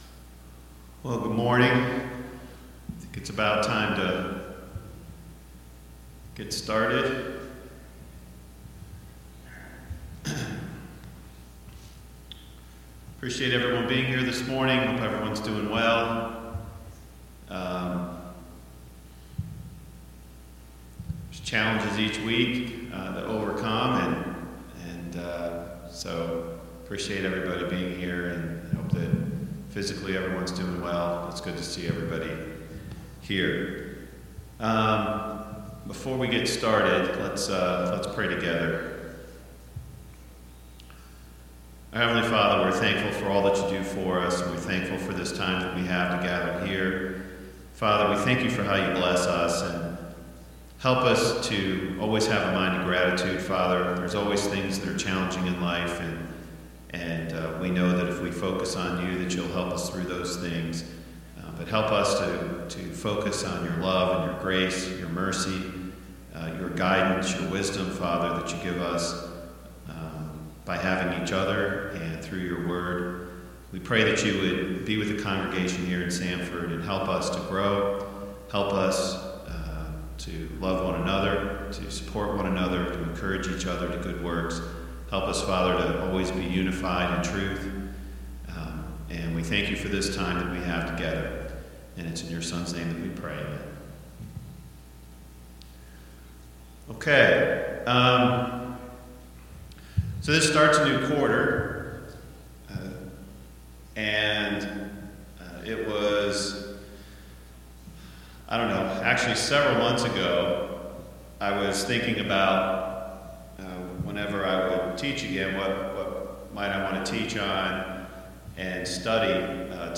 Service Type: Sunday Morning Bible Class Topics: The Need for Unity in the church